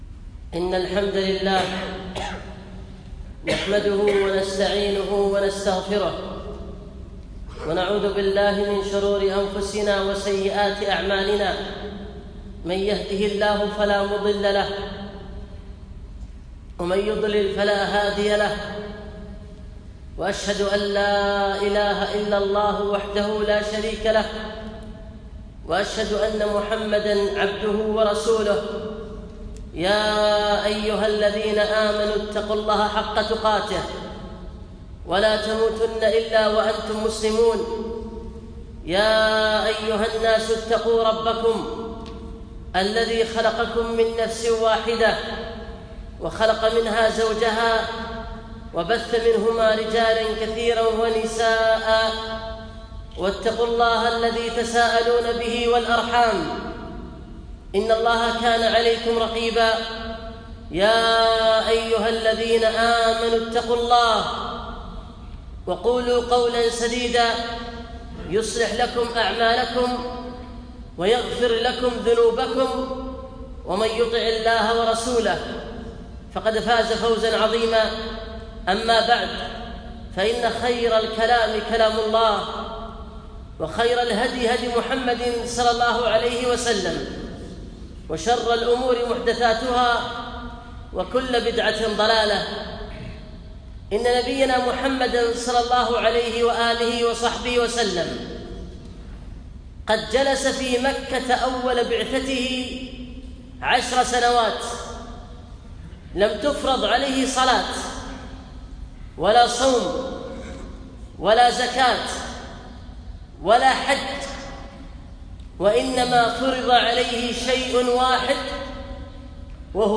خطبة - أهمية التوحيد